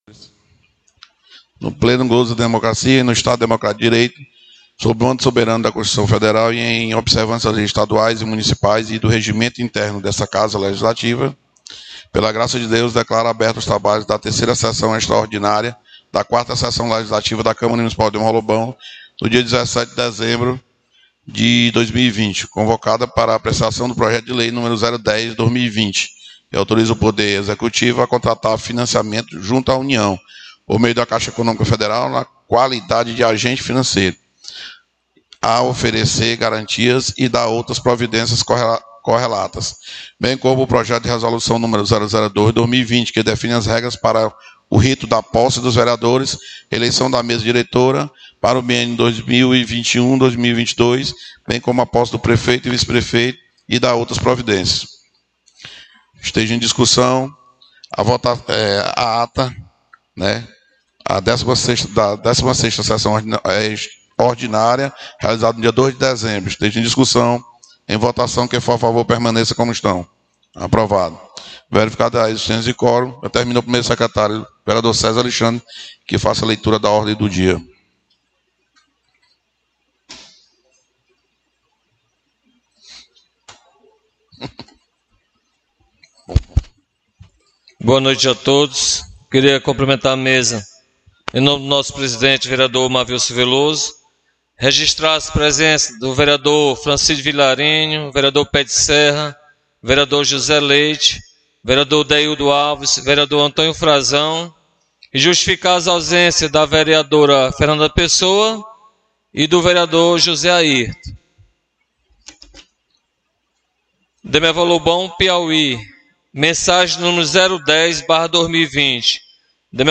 SESSÕES DA CÂMARA MUNICIPAL